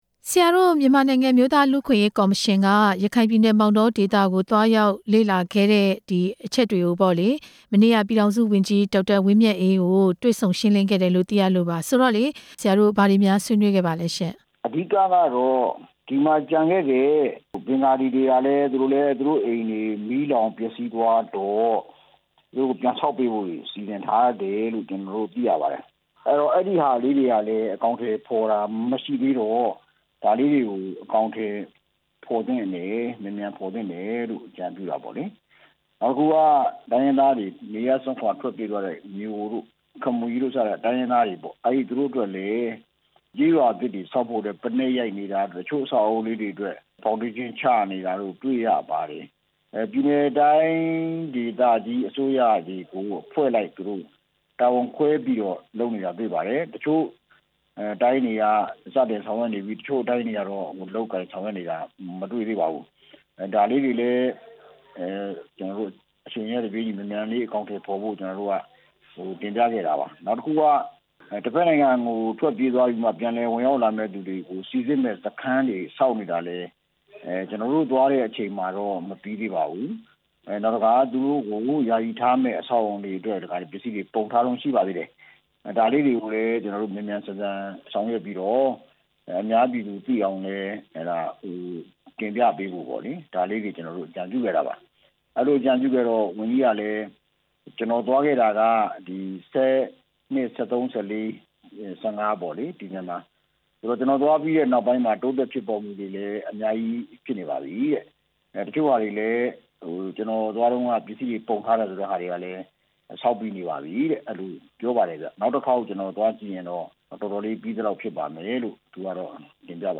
ထွက်ပြေးသွားသူတွေ ပြန်လက်ခံရေး လူ့အခွင့်အရေးကော်မရှင်နဲ့ မေးမြန်းချက်